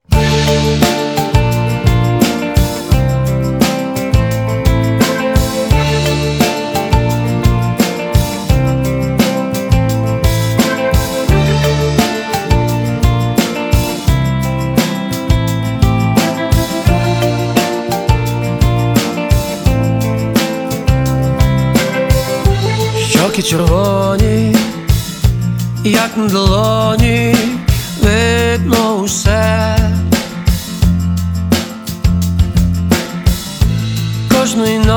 Жанр: Русская поп-музыка / Рок / Русский рок / Русские